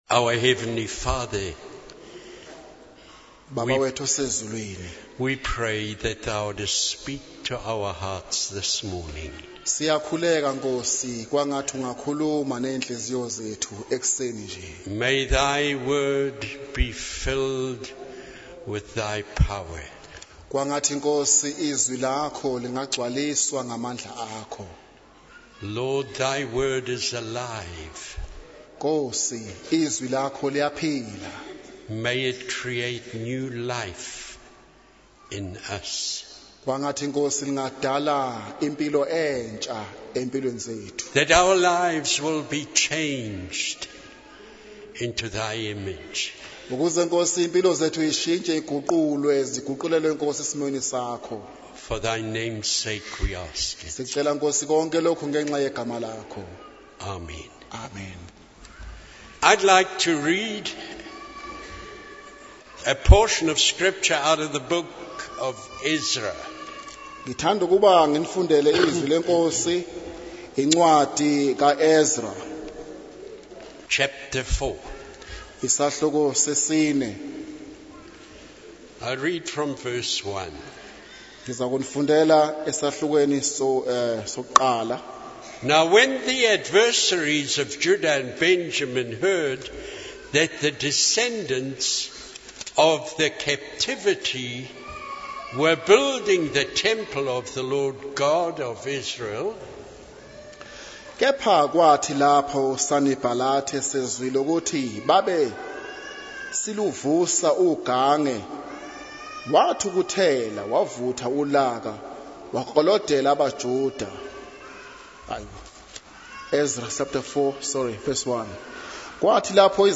In this sermon, the speaker emphasizes the importance of being watchful and discerning, as the enemy will try to destroy the work of God. When we dedicate our lives to the Lord, the enemy immediately goes on the offensive to hinder God's purpose in our lives. The speaker encourages Christians to remain steadfast and united, not allowing anything to separate them from God and from one another.